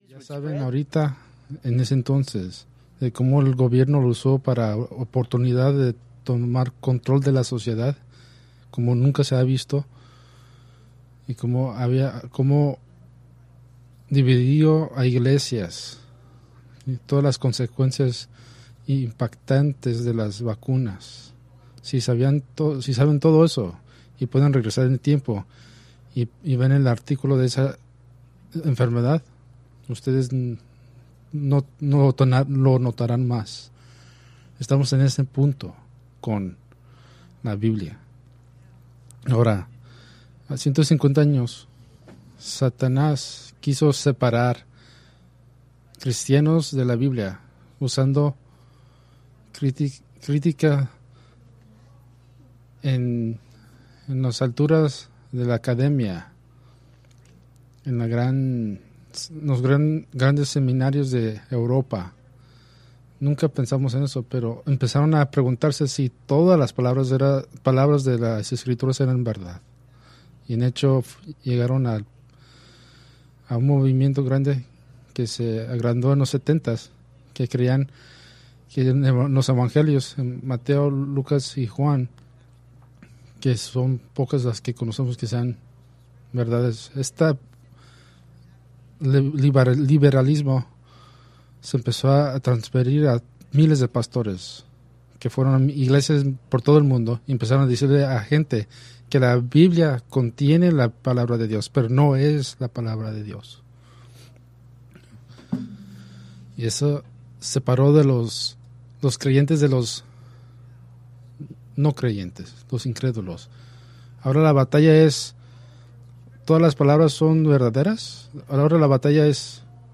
Preached March 29, 2026 from Escrituras seleccionadas